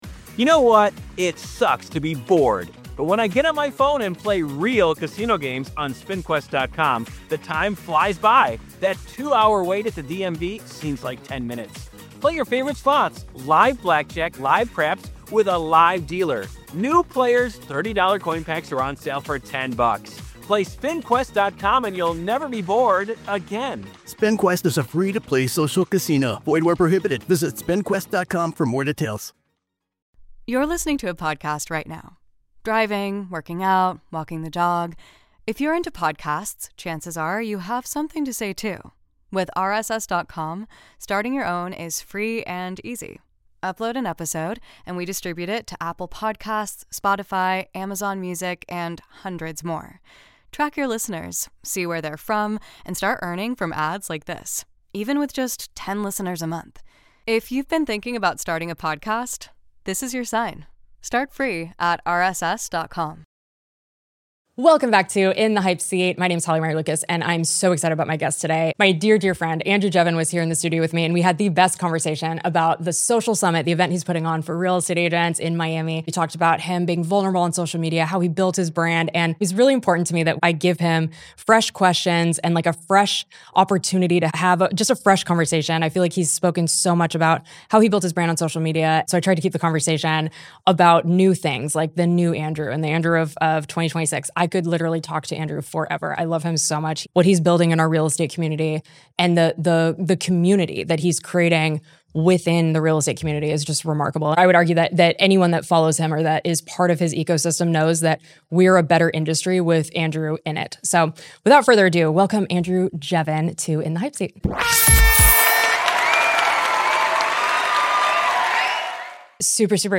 This is a layered conversation between two powerhouse industry leaders.